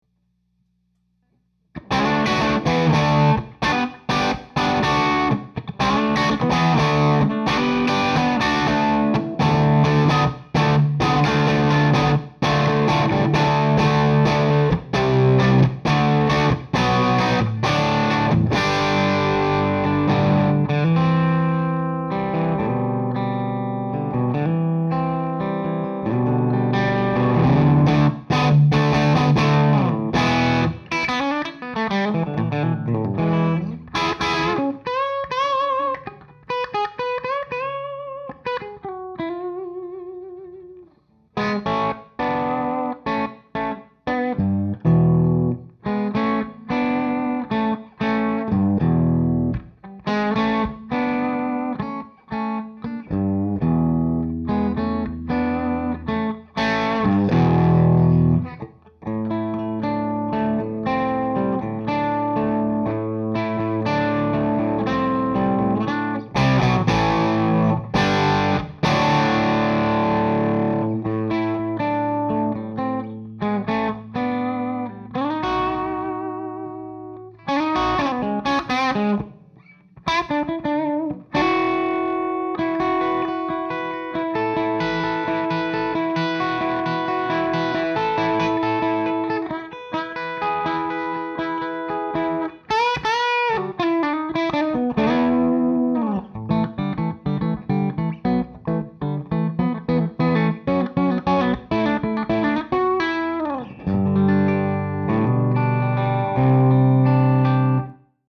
100w Scumback M75 demo: LP into Marshall modded Traynor
I think I dig the midrange tones of the M75, so its a keeper.
Any scratchyness you hear is coming from the PPIV master being down pretty low.
...and a quick lower gain one when my wife came in and told me to turn down...even lower then I already was! HA!
traynor_m75_lowergain.mp3